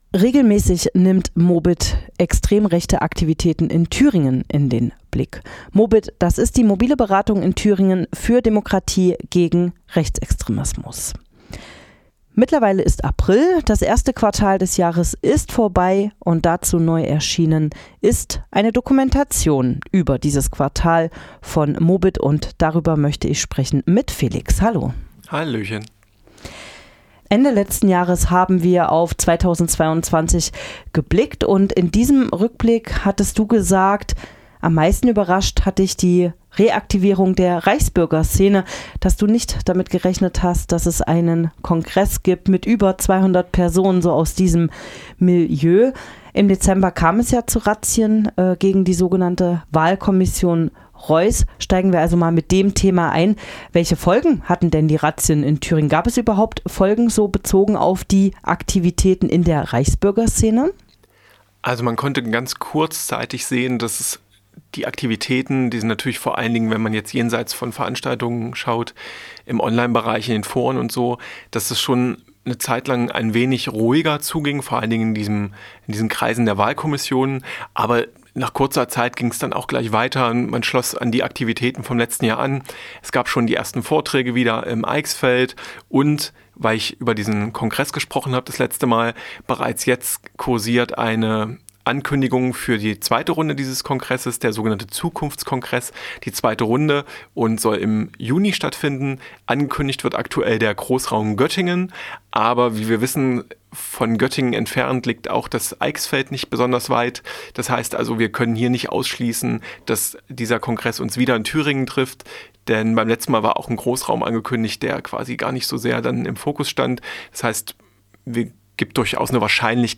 | Interview mit Mobit